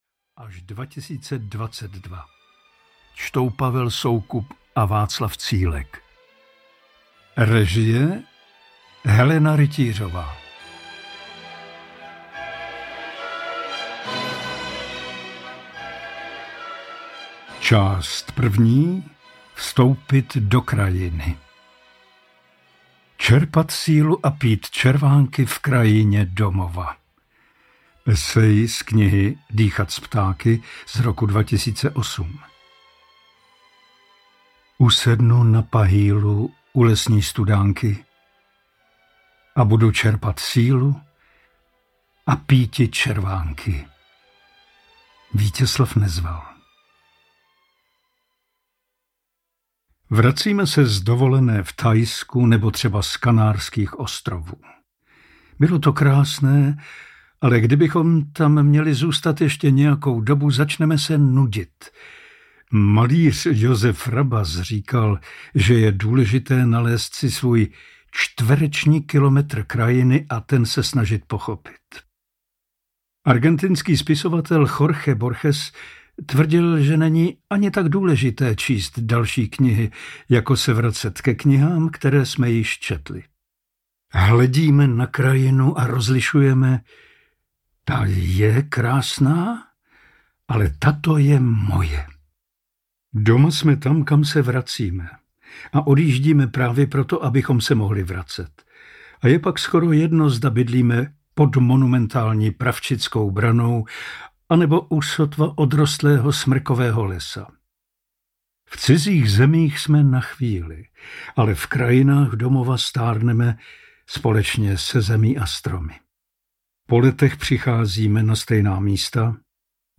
Knižní bestseller mapující jeho dvacetiletou autorskou činnost získal audiální podobu díky skvělé interpretaci herce Pavla Soukupa. Úvahy a články jsou uspořádány do pěti tematických oddílů, jež mají představit hlavní oblasti, které prostupují Cílkovým dílem: krajina jako celek; genius loci konkrétních míst; setkávání s lidmi, zvířaty i bájnými bytostmi; popis současného světa s jeho problémy a obav, kam se to řítíme; a nakonec texty o tom, jak z toho ven, které dávají naději.Jako bonus je zařazen nový text „O potřebě zázraků“ načtený přímo autorem.
Vypočujte si ukážku audioknihy